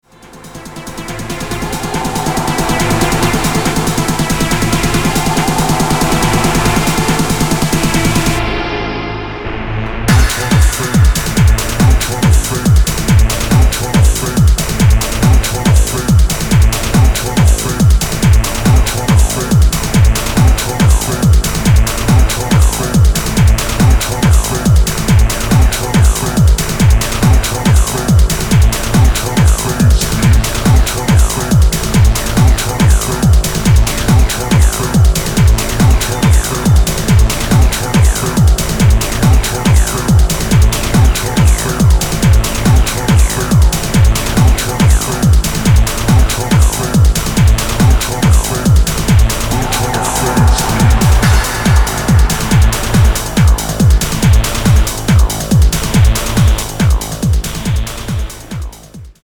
Electro Techno Acid